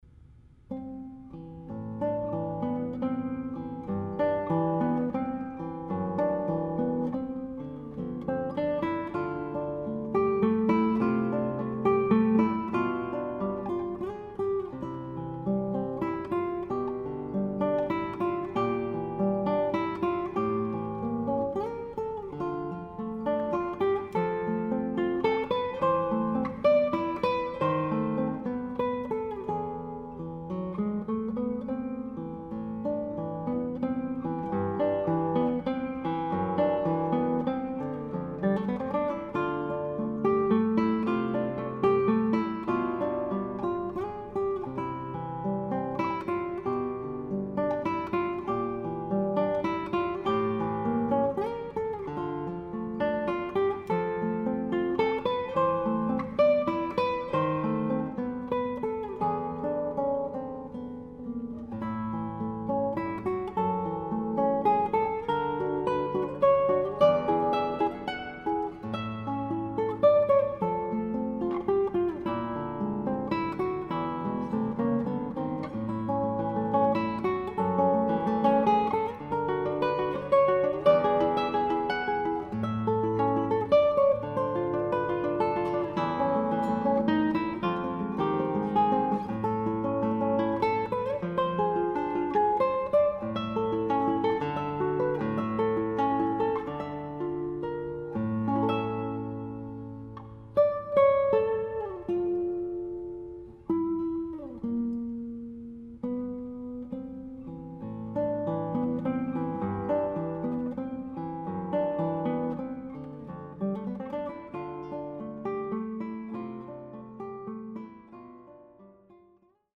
Guitar
tongue-in-cheek musical parodies